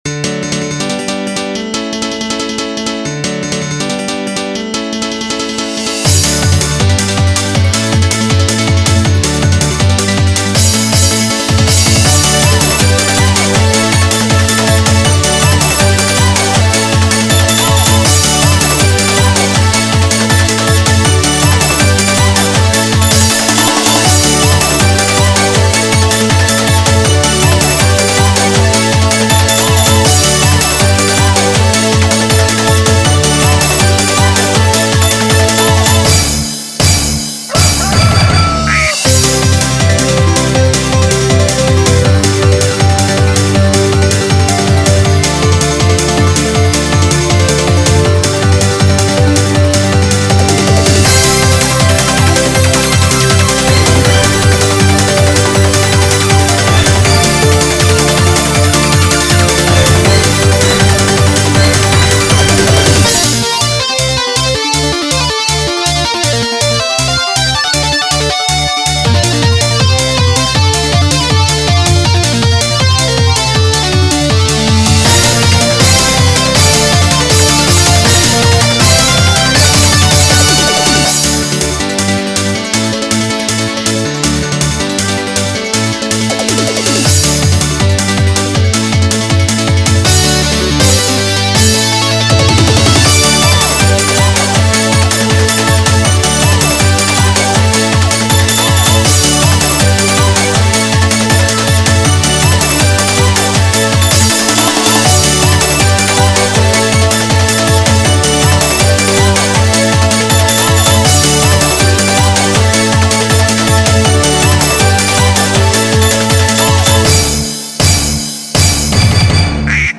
BPM160
Audio QualityPerfect (High Quality)
Comments[HAPPY EUROBEAT]